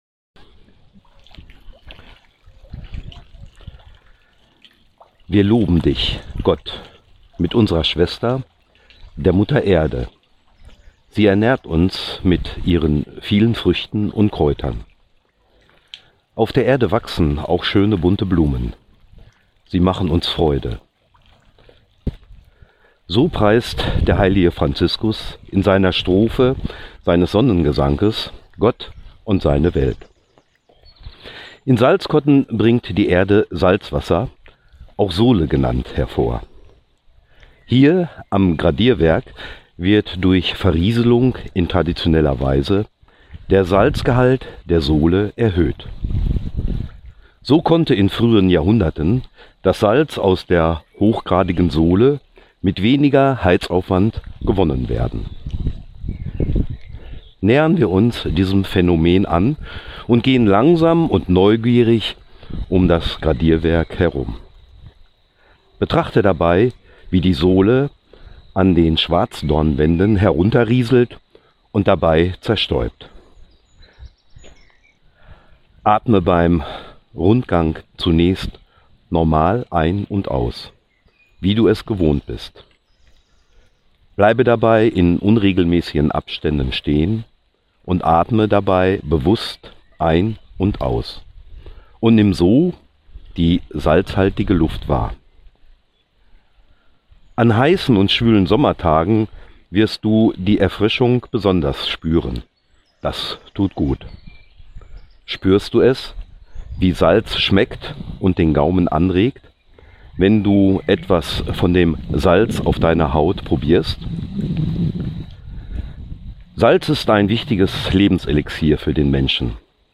Salz-Atem-Meditation-am-Gradierwerk-mp3.mp3